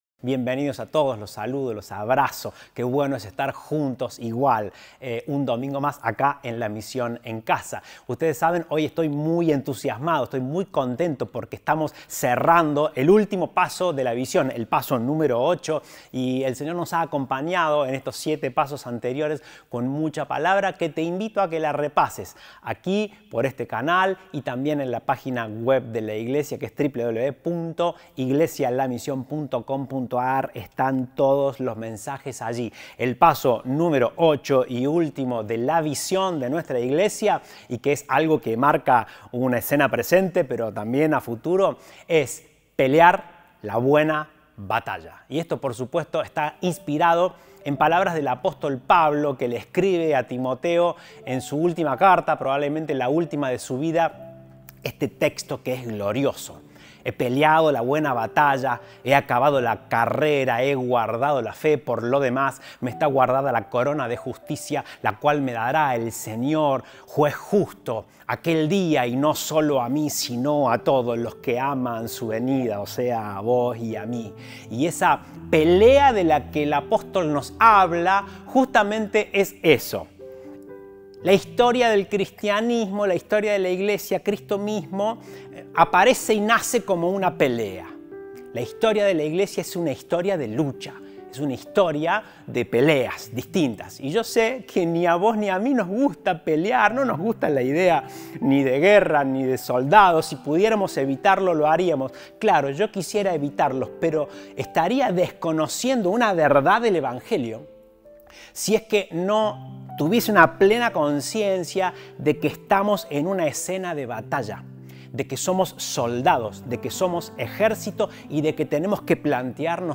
Compartimos el mensaje del Domingo 30 de Mayo de 2021 El siguiente es el video de la transmisión de la reunión virtual.